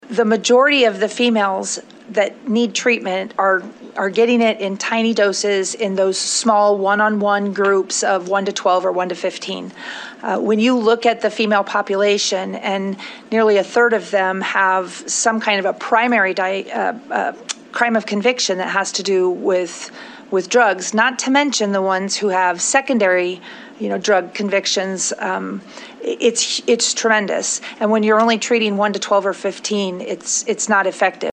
Corrections Department Secretary Kellie Wasko told the Appropriations Committee its difficult to provide needed drug and alcohol rehabilitation in that type of situation.